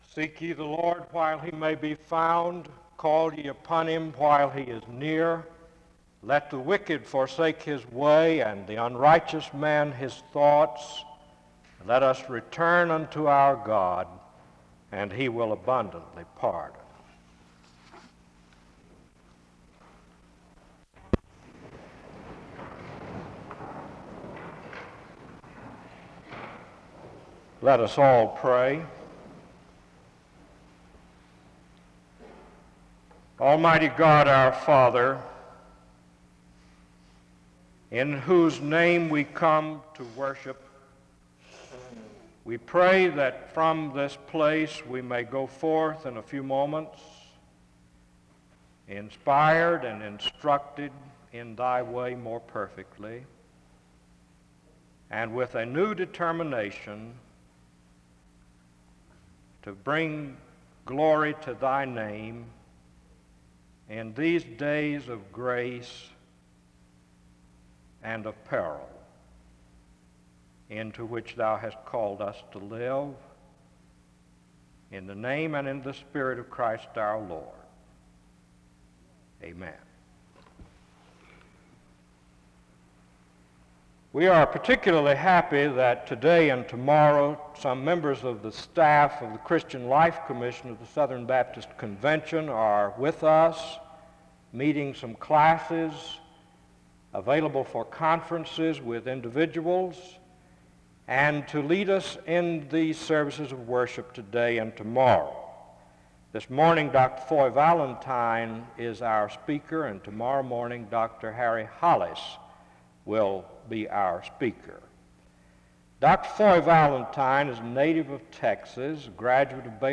The service begins with prayer (0:00-1:20).
This chapel is distorted from 17:39-17:47 and 18:04-20:59.